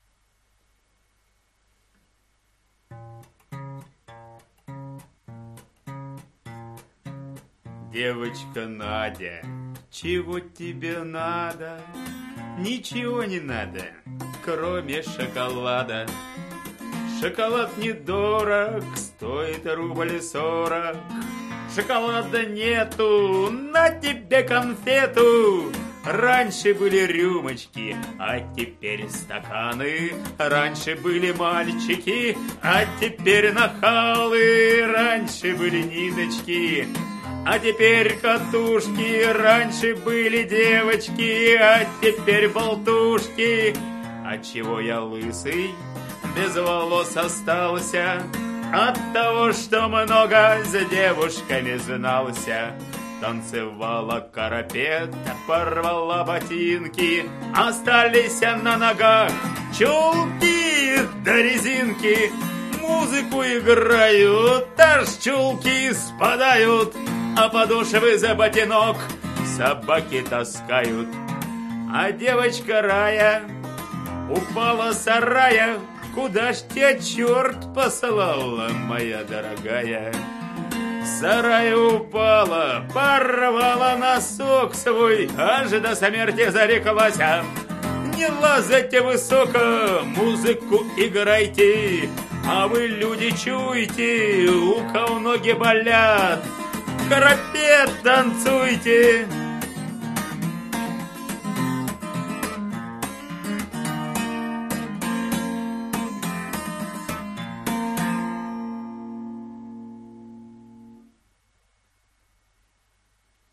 и гитарный вариант